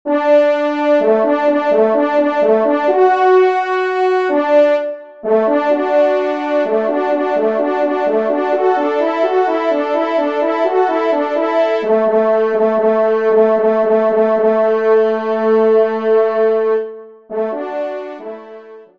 Genre : Musique Religieuse pour Trois Trompes ou Cors
Pupitre 2°Trompe